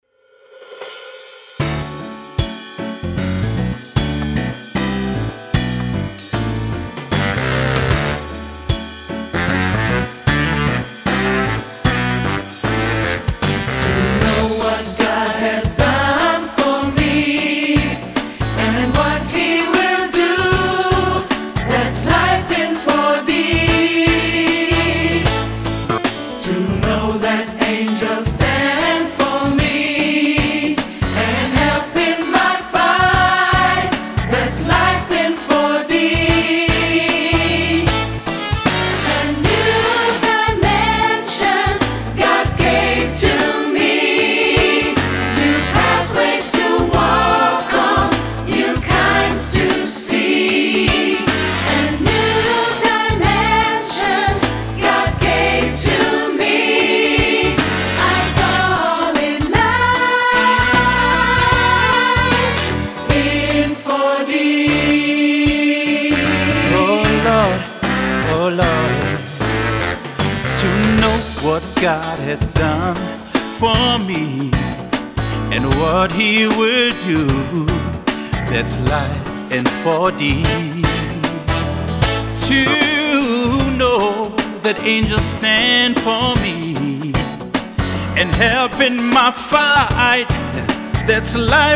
Gesangssatz
• SAB (Solist ad lib.) + Piano